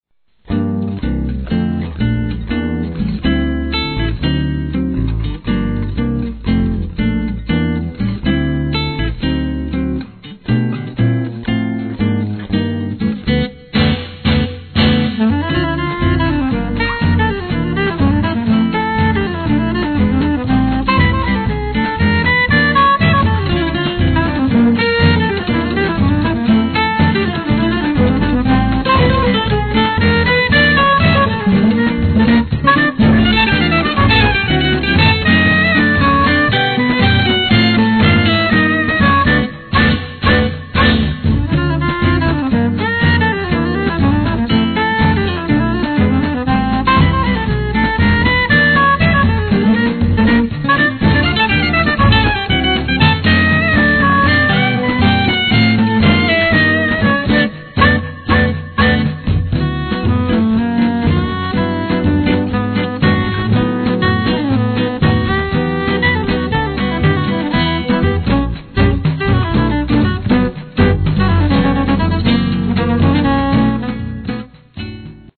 festive and emotional music